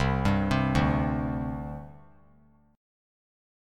Csus2 chord